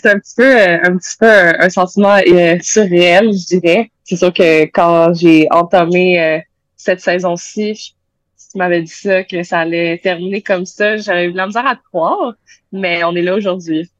Quelques heures après sa victoire, elle a confié à l’émission VIA l’été qu’elle était bien heureuse de sa performance.